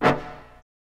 brass hit 2.wav